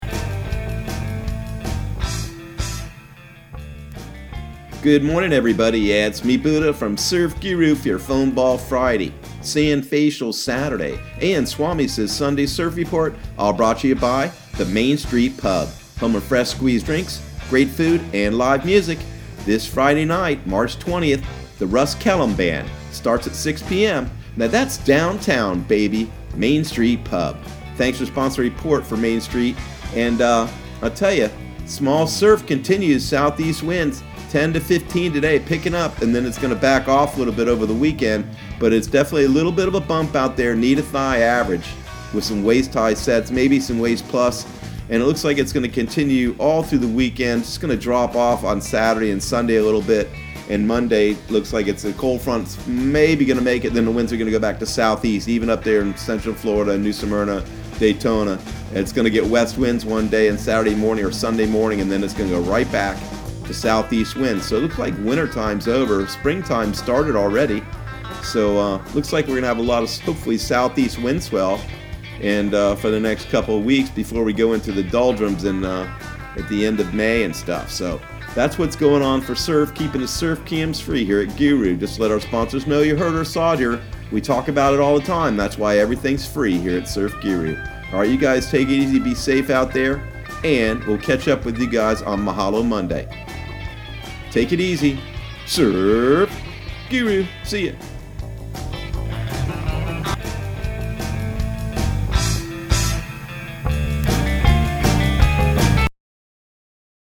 Surf Guru Surf Report and Forecast 03/20/2020 Audio surf report and surf forecast on March 20 for Central Florida and the Southeast.